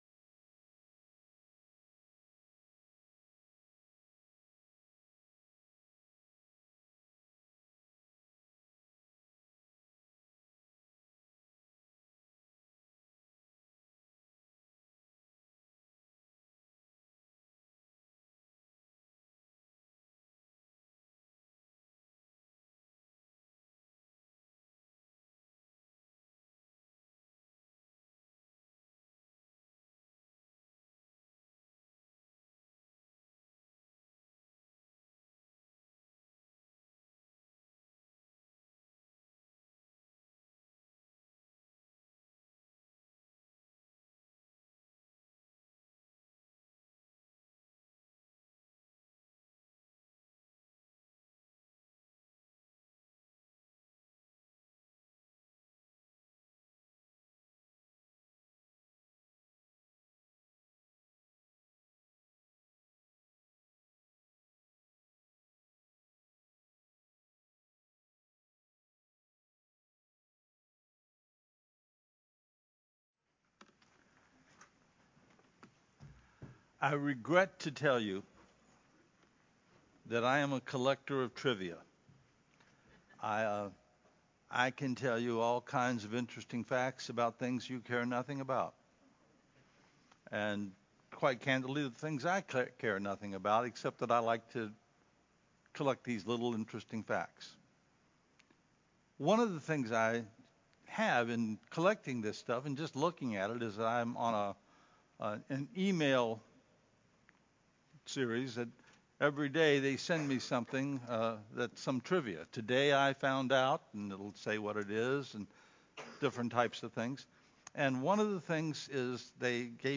Mothers-Day-Sermon-Audio-8-May-2022-CD.mp3